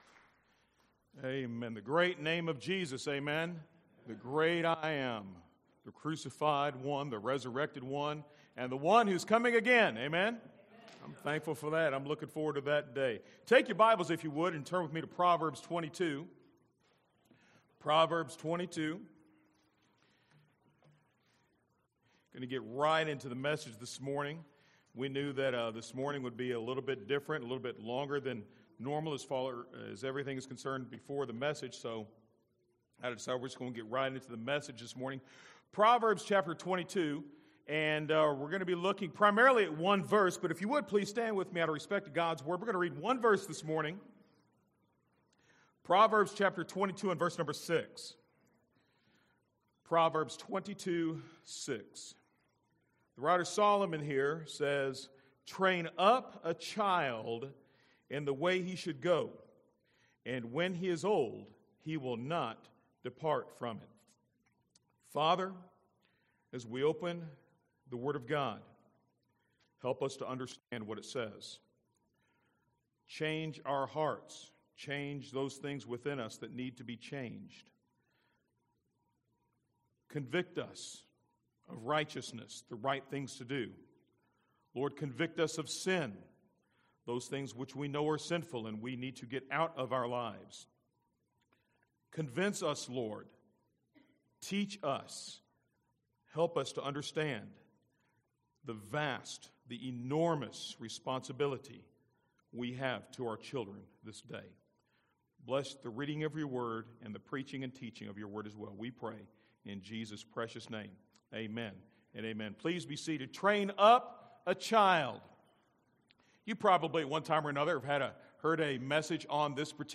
From Series: "Individual Sermons"